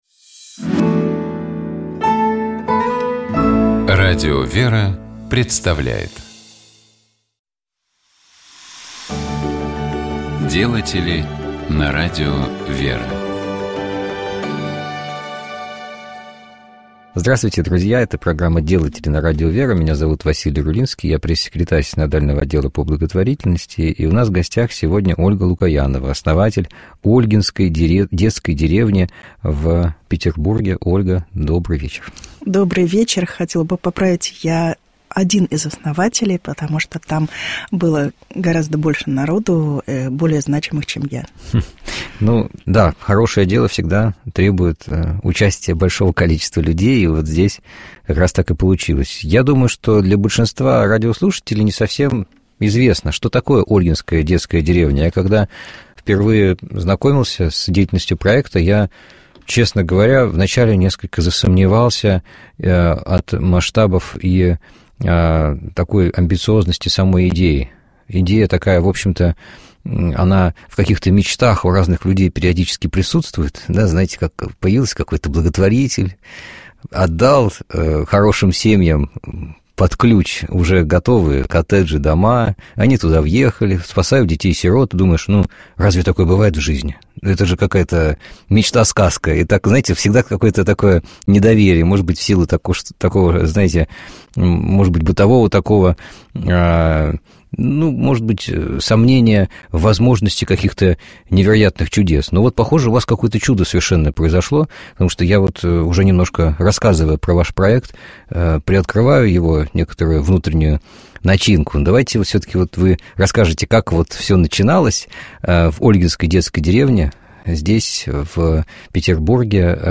Мы говорили с нашим гостем о его приходе к вере и о пути к священническому служению.